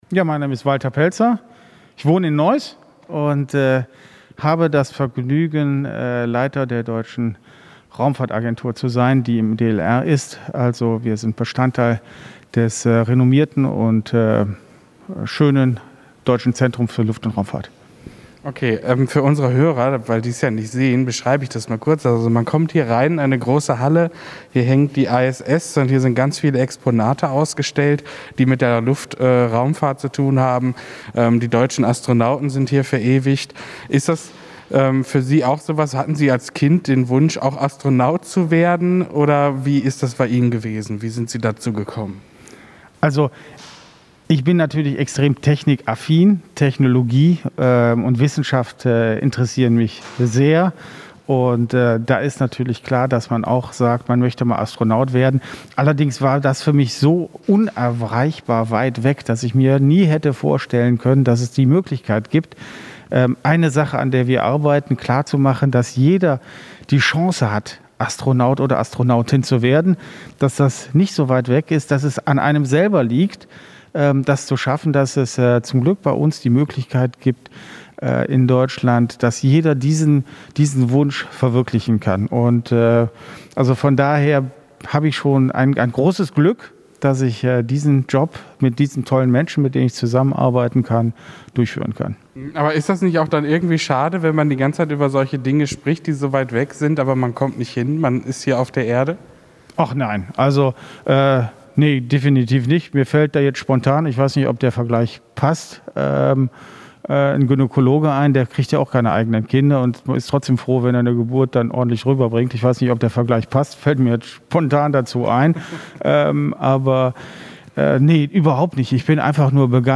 Wir haben ihn im Zentrum für Luft- und Raumfahrt in Bonn besucht.
Das komplette Interview mit ihm bekommt ihr hier in unserem Podcast: